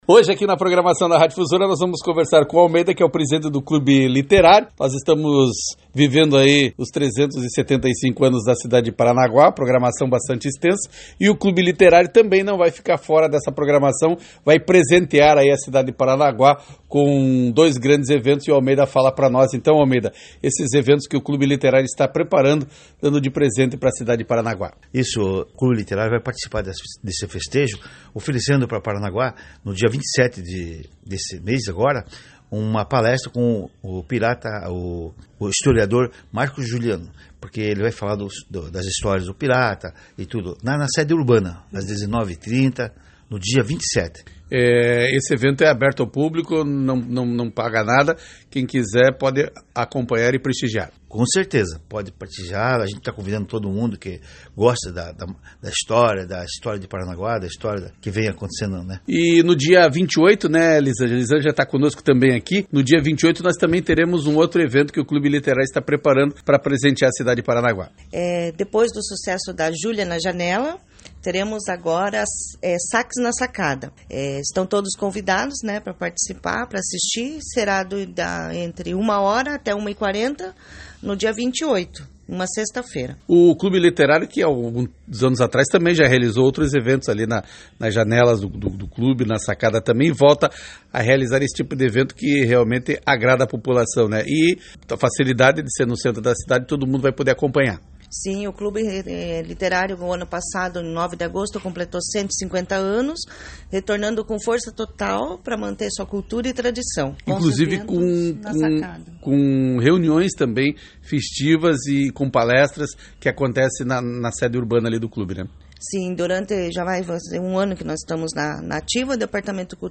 A equipe de Jornalismo da Difusora conversou com a Diretoria do Clube, Confira: Um outro